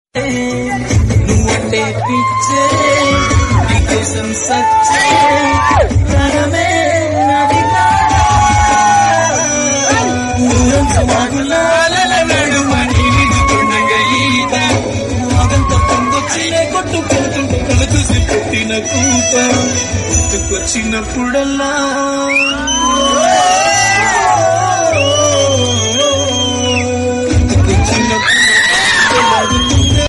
a beautiful Telugu love melody with soft romantic vibes.
romantic ringtone